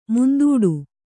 ♪ mundūḍu